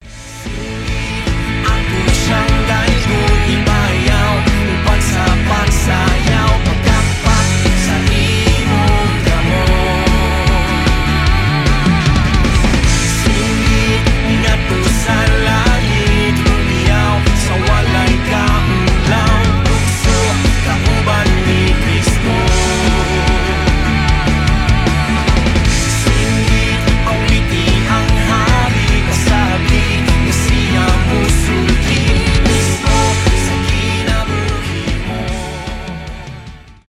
pop rock
христианский рок